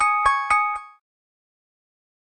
Alarm_Classic.ogg